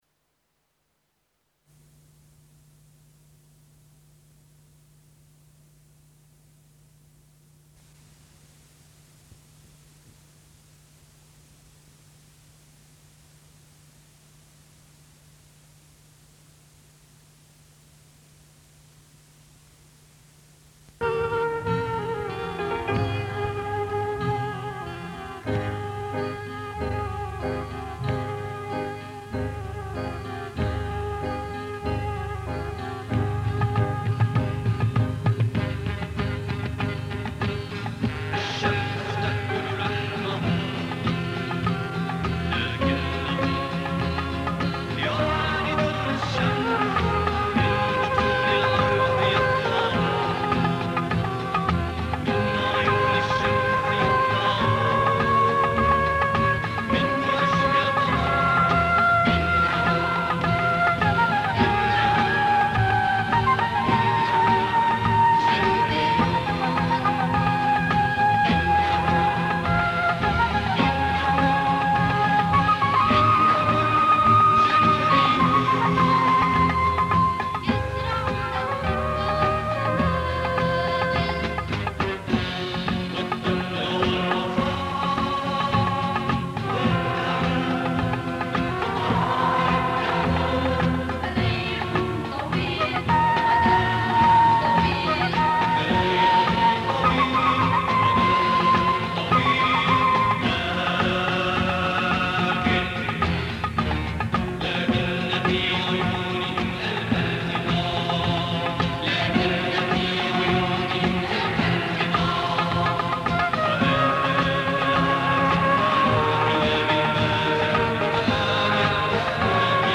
Un fichier mp3 contenant une face de la cassette originale.